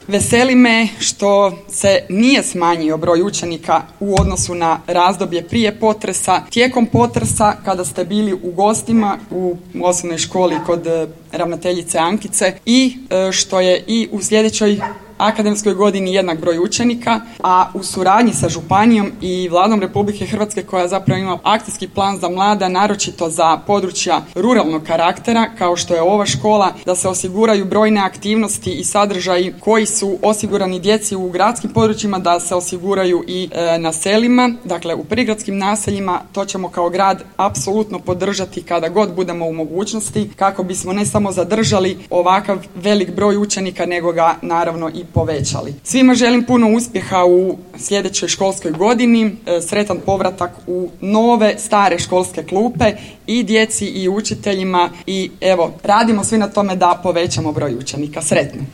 U petak, 28. srpnja 2023. godine, u Nebojanu je svečano otvorena novoizgrađena zgrada Područne škole Osnovne škole Ivan Goran Kovačić Gora.
Gradonačelnica Petrinje Magdalena Komes navodi kako je otvorenje ove škole od velike važnosti